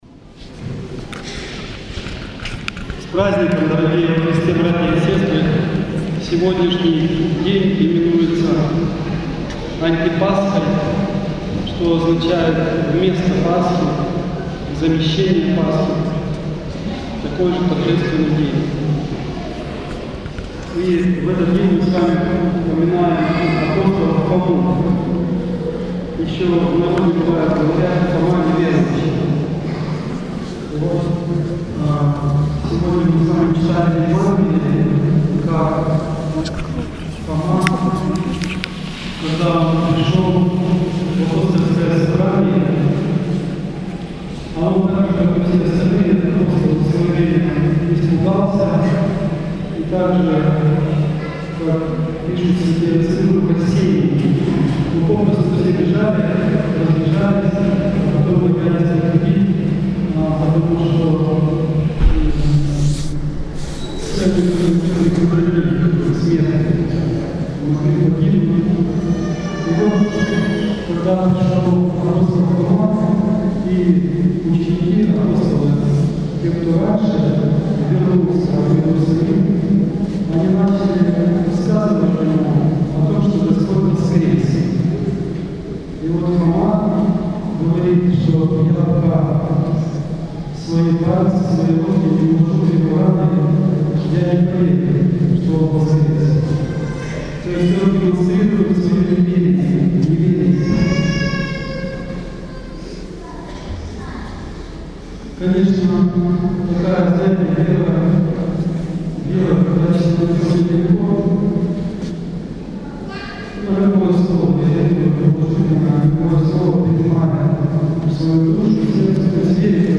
Проповедь на Антипасху, неделю апостола Фомы.